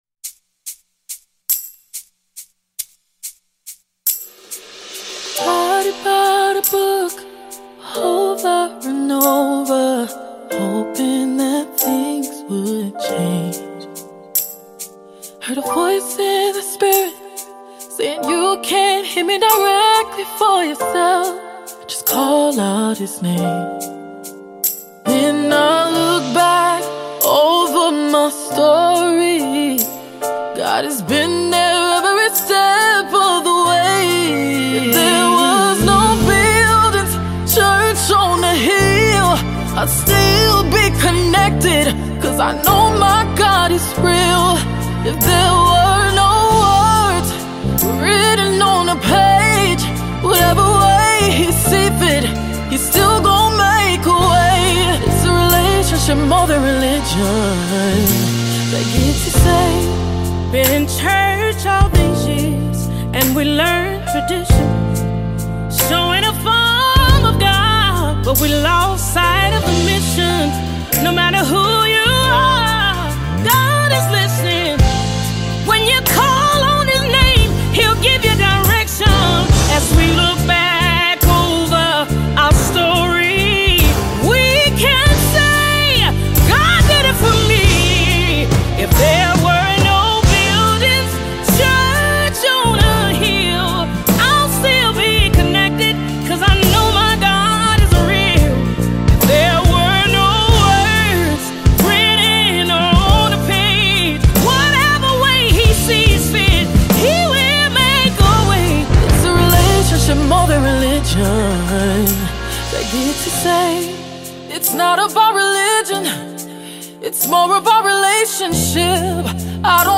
Mp3 Gospel Songs
With her powerful vocals and emotive delivery
soulful performance